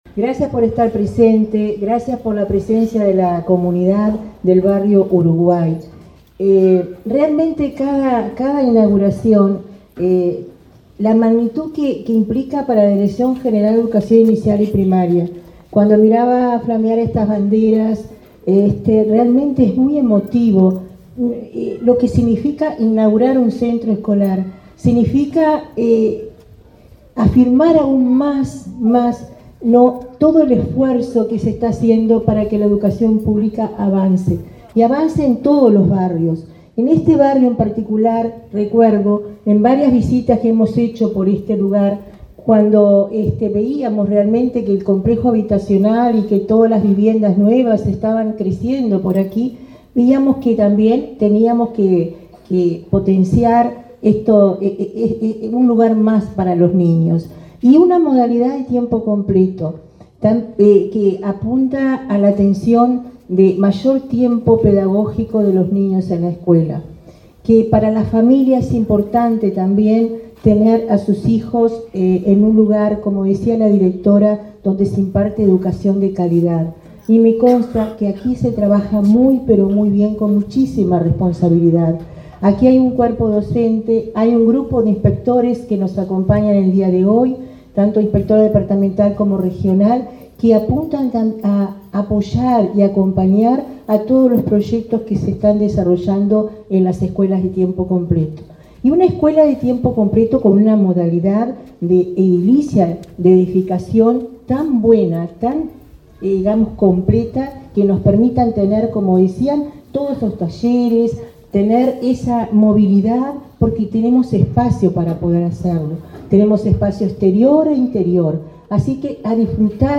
Palabras de autoridades de ANEP en Salto
La directora de Primaria, Olga de las Heras, y el presidente de la Administración Nacional de Educación Pública (ANEP), Robert Silva, participaron,